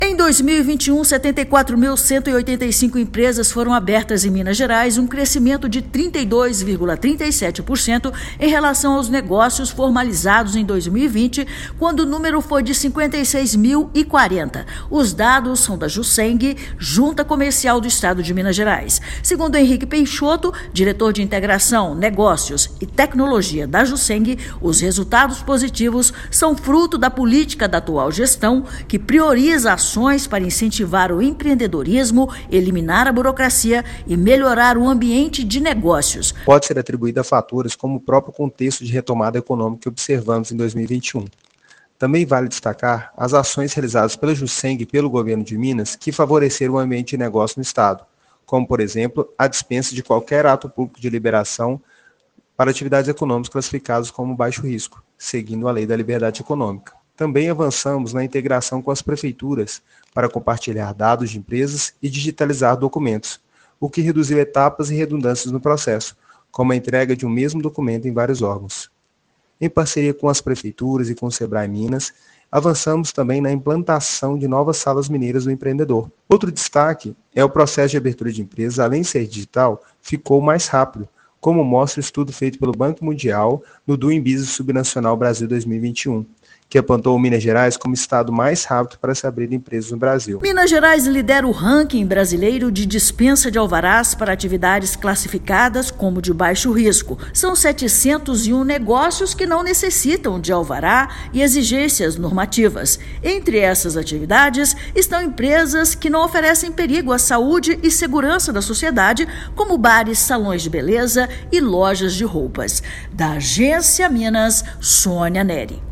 Em 2021, 74.185 empresas foram abertas em Minas Gerais, um crescimento de 32,37% em relação aos negócios formalizados em 2020, quando o número foi de 56.040. Ouça matéria de rádio.